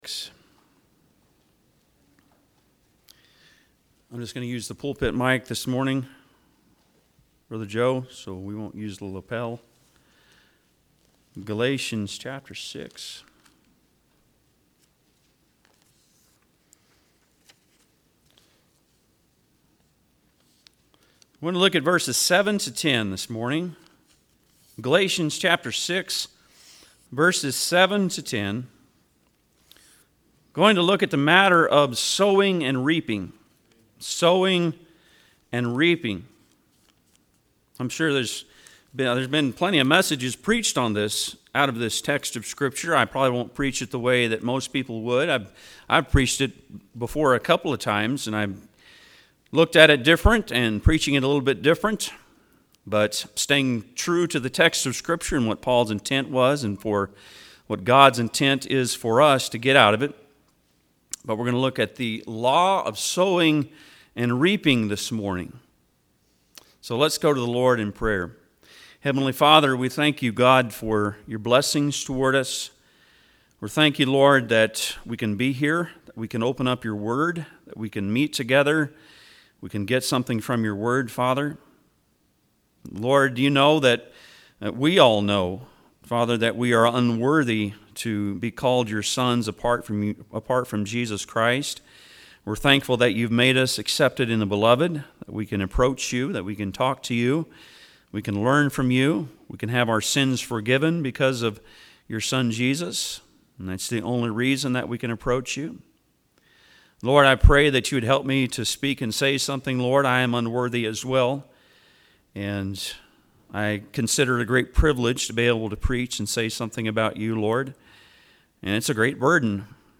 Passage: Galatians 6:7-10 Service Type: Sunday am